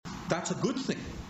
Bernie saying lining up for food is good